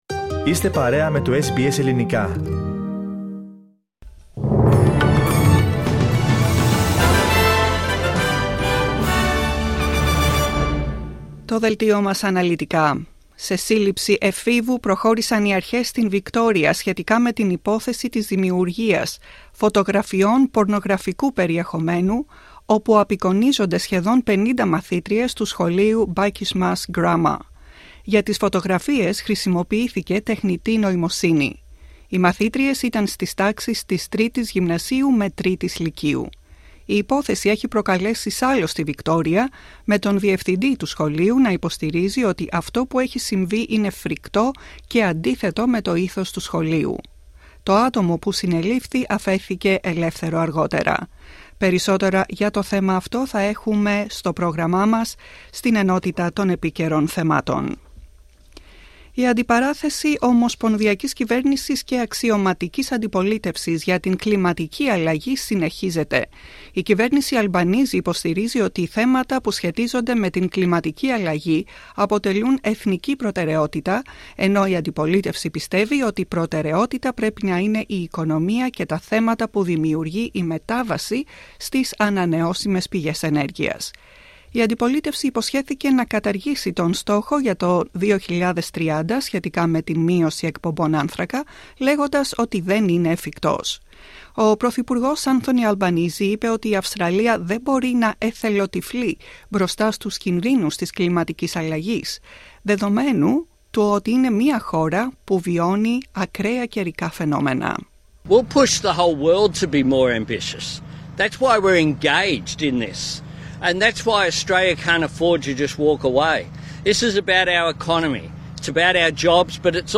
Δελτίο ειδήσεων Τετάρτη 12 Ιoυλίου 2024
Ακούστε το κεντρικό δελτίο ειδήσεων του Ελληνικού Προγράμματος.